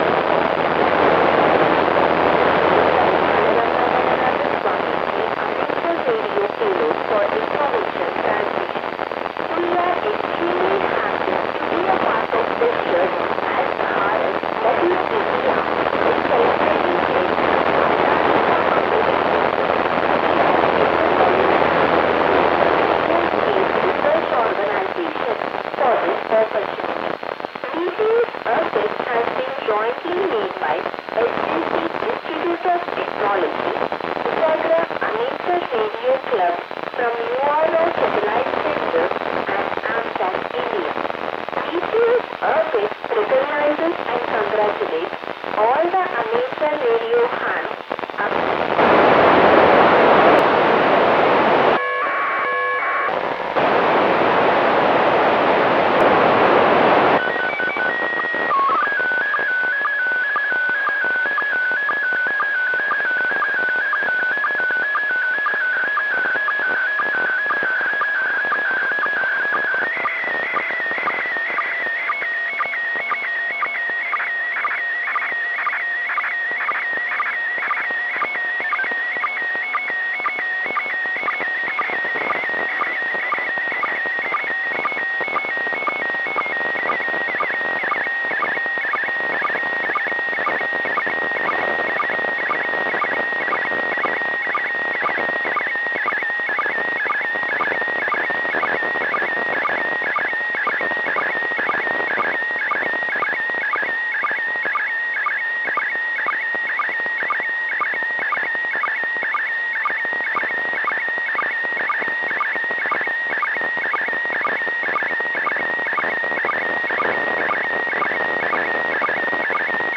Enclosed recording includes the pre recorded audio message, a greeting message & limited Telemetry in APRS packet format and a pre loaded SSTV Image.